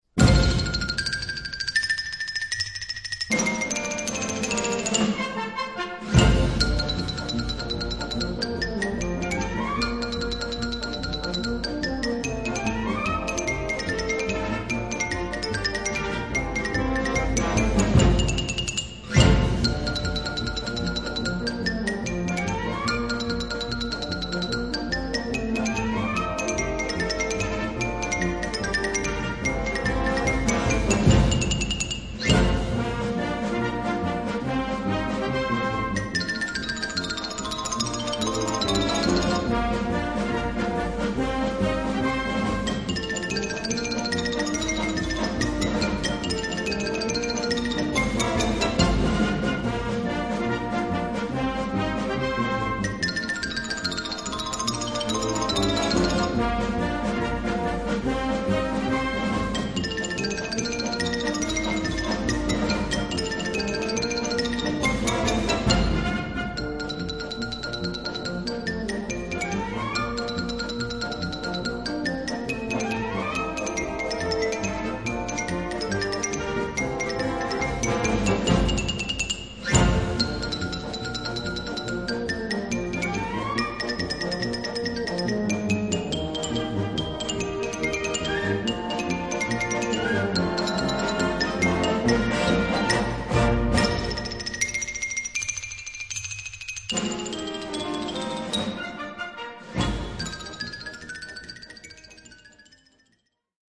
Gattung: Xylophonsolo
Besetzung: Blasorchester
ist ein spritziges Solo für das Xyolphon und seinen Spieler